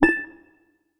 Mein bottle pling
bell clink compact ding glass hit impact metal sound effect free sound royalty free Sound Effects